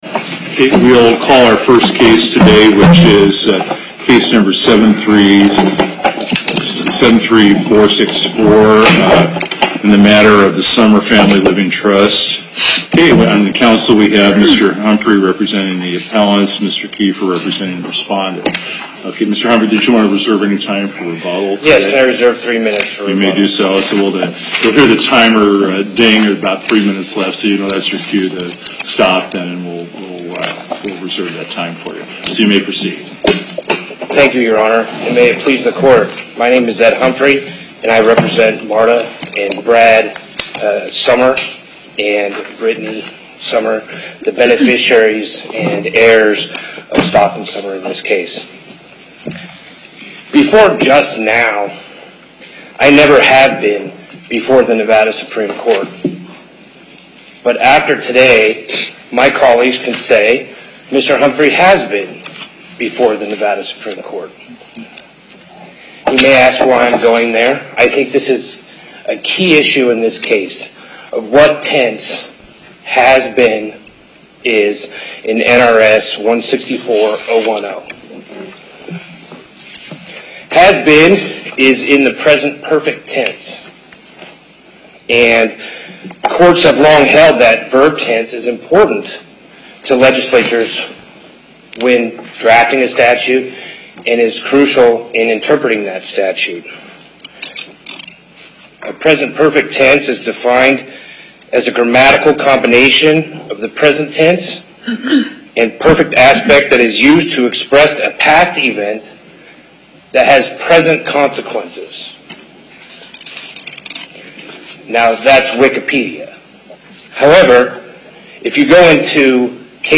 Location: Las Vegas Before the En Banc Court, Chief Justice Gibbons Presiding